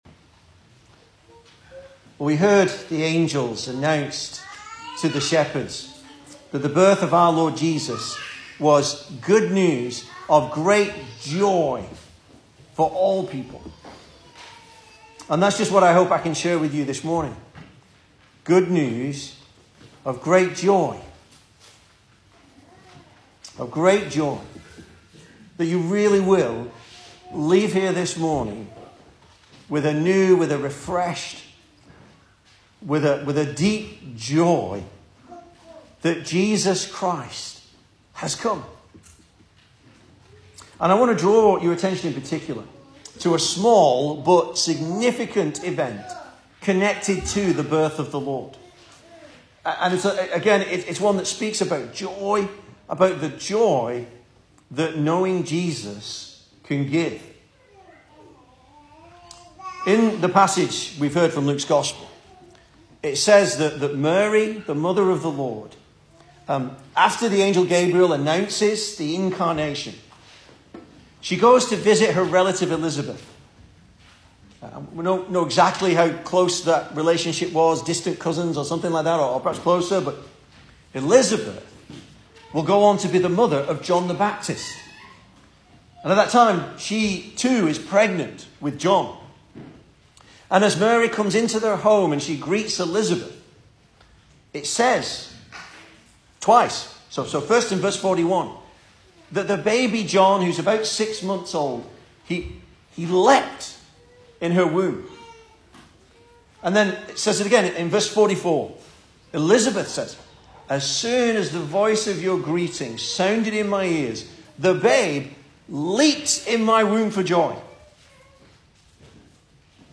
Service Type: Sunday Morning
Christmas Sermons